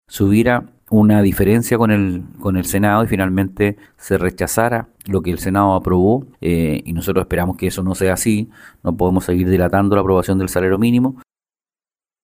El diputado de Renovación Nacional, Frank Sauerbaum, integrante de la Comisión de Trabajo y Seguridad Social, explicó que el proyecto contempla el alza del sueldo mínimo y otros beneficios asociados, pero que su sector, al igual que el Senado, rechazó la creación del Observatorio del Costo de la Vida.